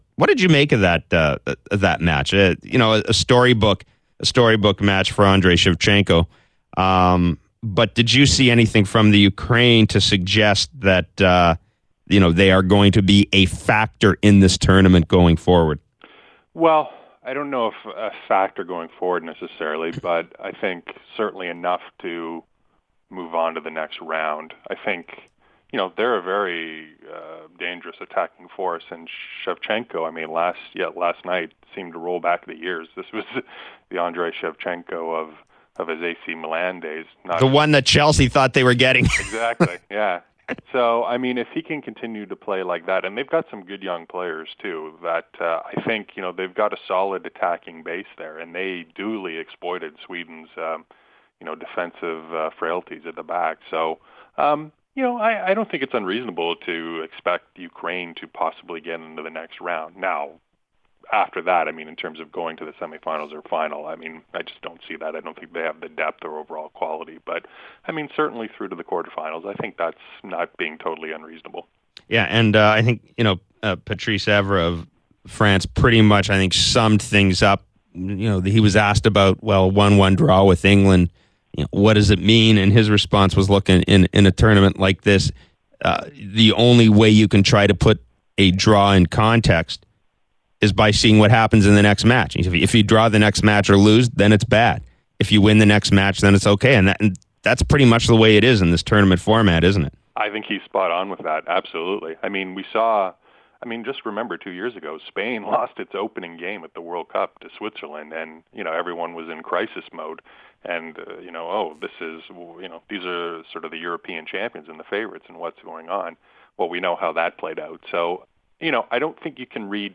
soccer reporter